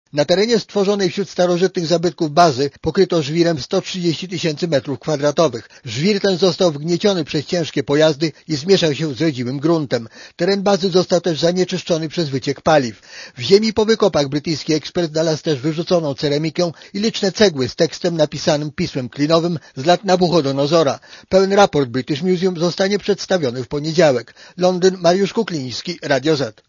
Korespondencja z Londynu